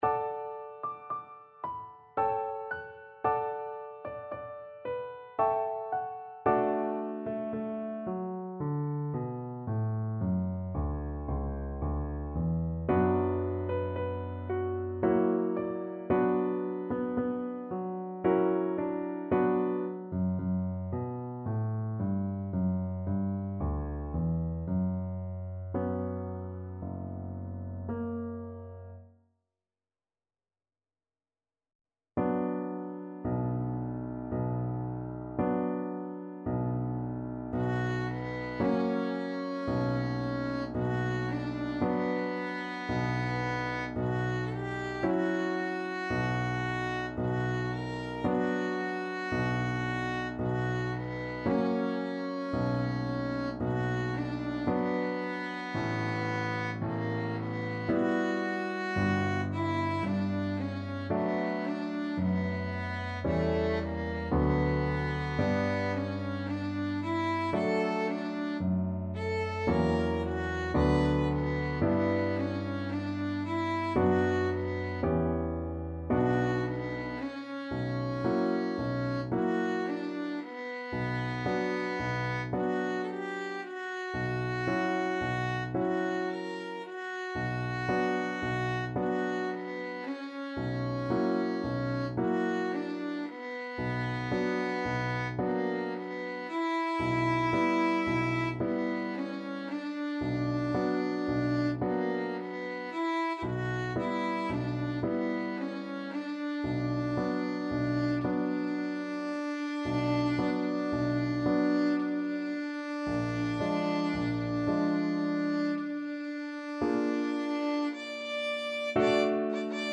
Classical Tchaikovsky, Pyotr Ilyich Serenade melancolique, Op.26 Violin version
Violin
D major (Sounding Pitch) (View more D major Music for Violin )
~ = 56 Andante
3/4 (View more 3/4 Music)
Classical (View more Classical Violin Music)
tchaik_serenade_melancolique_VLN.mp3